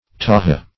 taha - definition of taha - synonyms, pronunciation, spelling from Free Dictionary Search Result for " taha" : The Collaborative International Dictionary of English v.0.48: Taha \Ta"ha\, n. The African rufous-necked weaver bird ( Hyphantornis texor ).